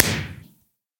1.21.4 / assets / minecraft / sounds / fireworks / blast1.ogg
blast1.ogg